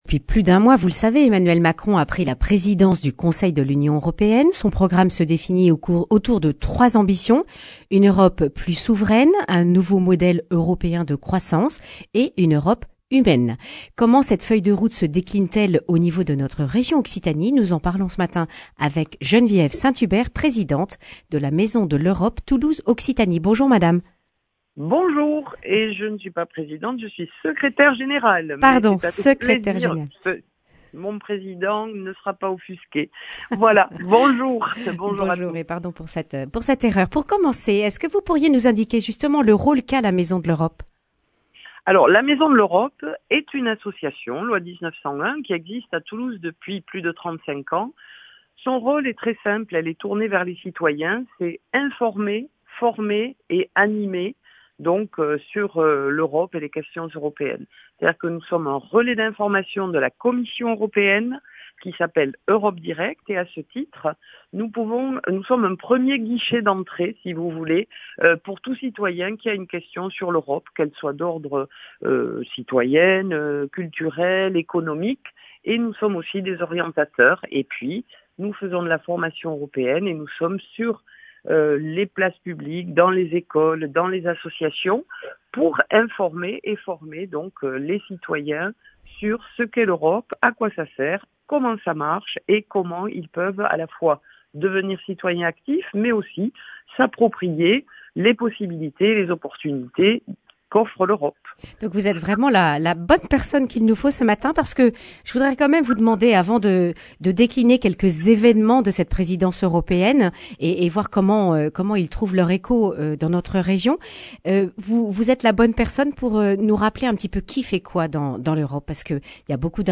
Tous les évènements à retrouver dans cette interview.